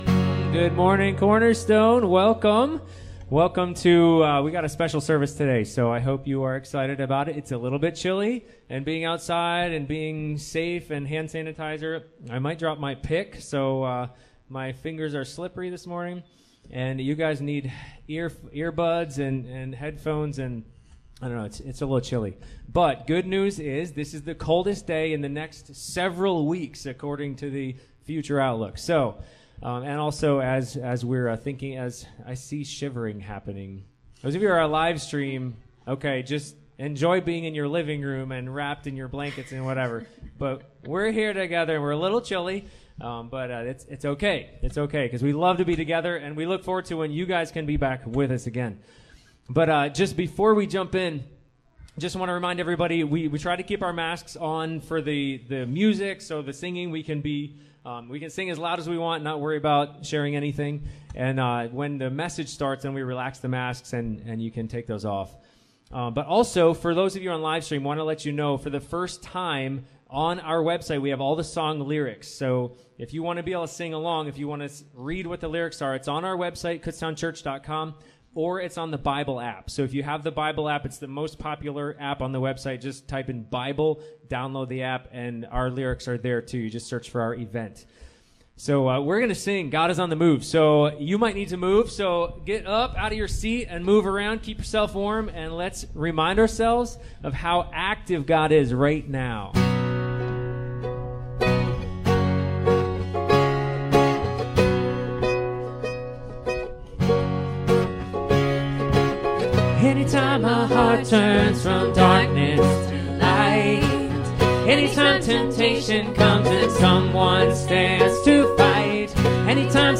Bible Text: Hosea 14:1-3 | Preacher: Guest Speaker | Series: CCC Sermons |
Series: CCC Sermons
Service Type: Sunday Morning